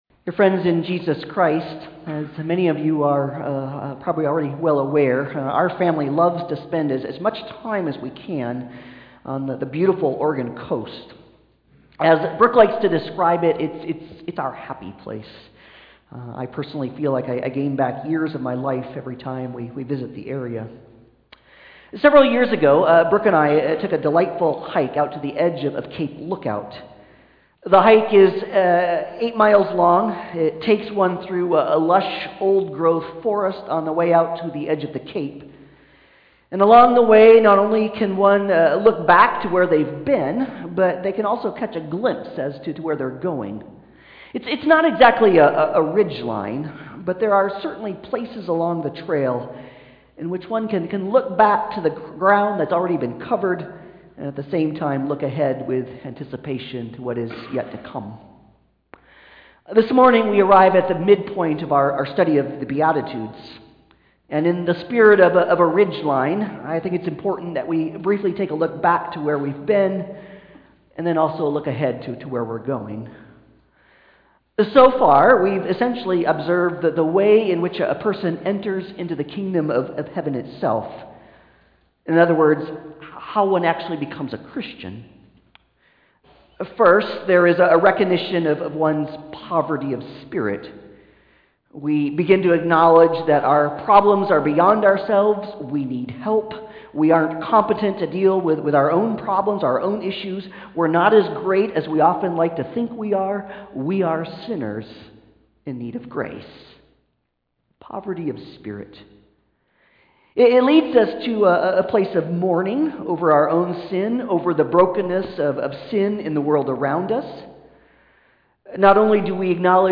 Passage: Matthew 5:1-7 Service Type: Sunday Service